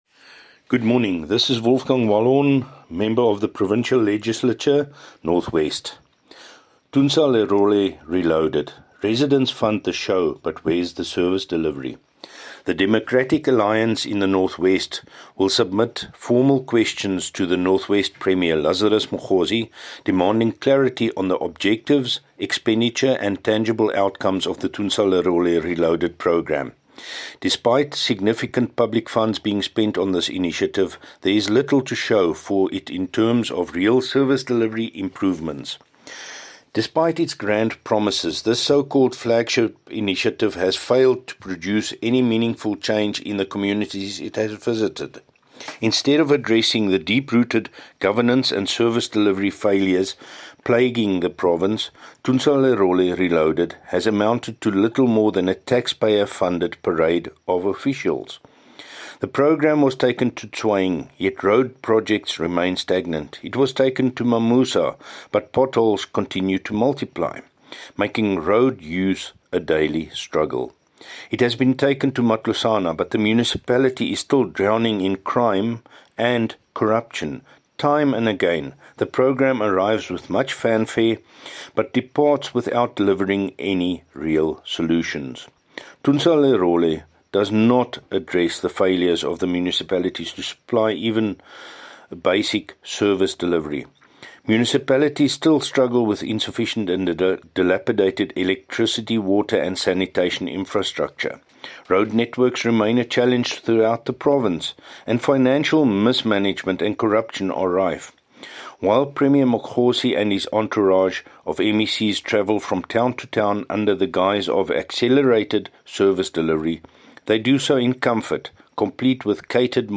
Issued by Wolfgang Wallhorn – DA Spokesperson on the Office of the Premier in the North West Provincial Legislature
Note to Broadcasters: Please find attached soundbites in